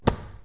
/ cdmania.iso / sounds / impacts / sharp1.wav ( .mp3 ) < prev next > Waveform Audio File Format | 1996-04-15 | 5KB | 1 channel | 22,050 sample rate | 0.06 seconds
sharp1.wav